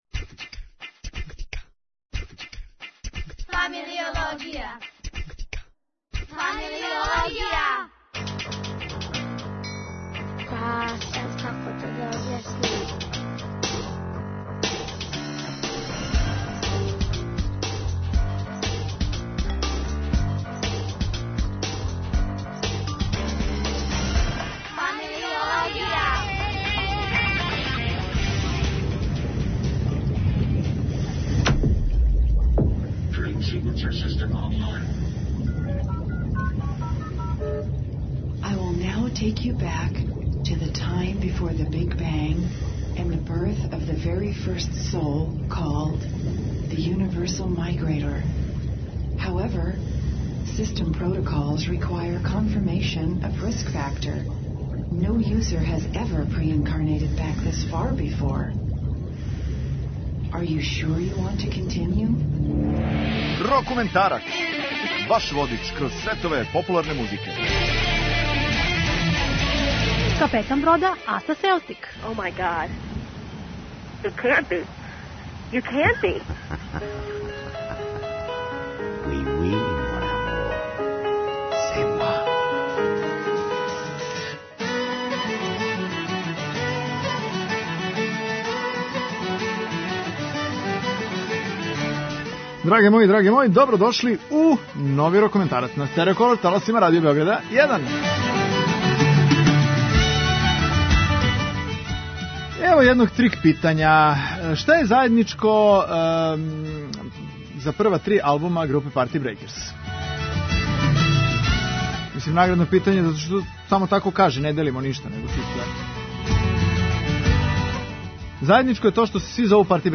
Чист рокенрол!